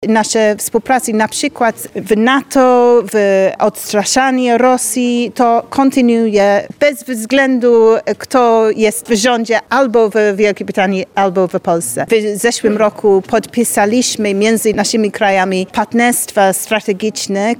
Ambasador Wielkiej Brytanii w Polsce Anna Clunes gościła dziś w Lublinie. Okazją było spotkanie ze studentami zorganizowane na Wydziale Politologii i Dziennikarstwa UMCS.